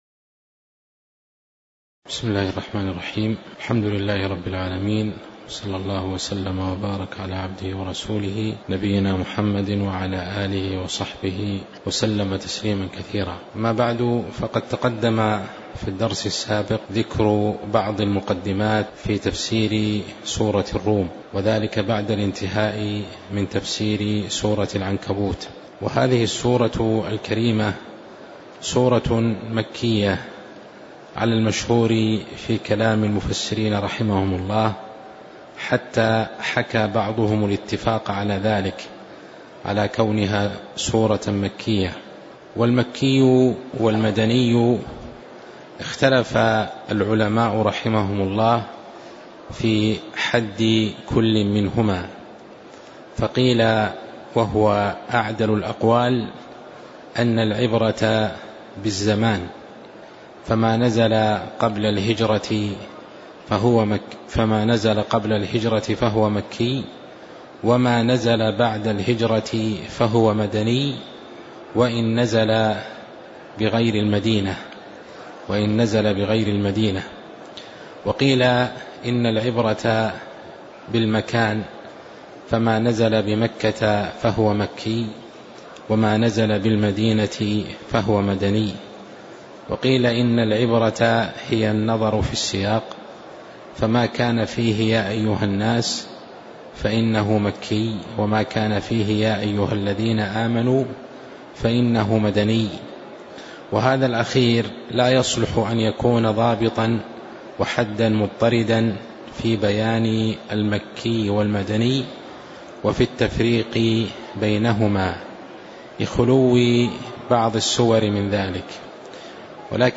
الدروس العلمية بالمسجد الحرام والمسجد النبوي